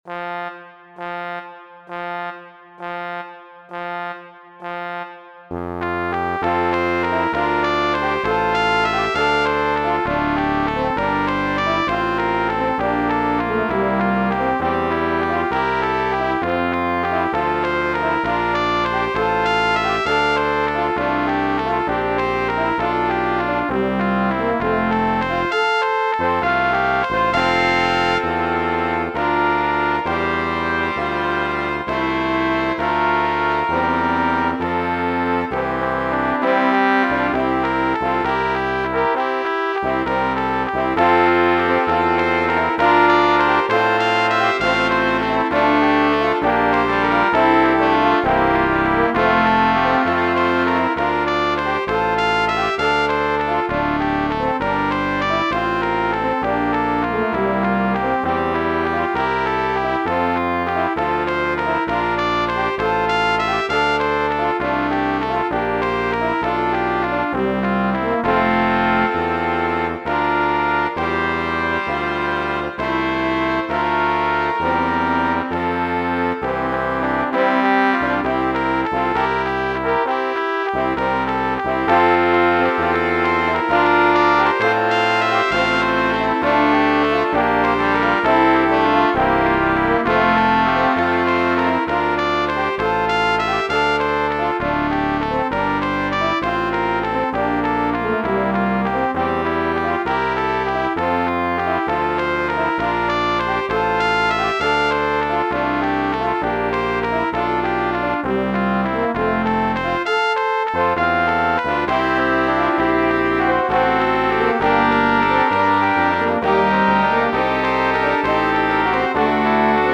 Acht Stimmen in zwei Chören      nach oben